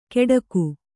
♪ keḍaku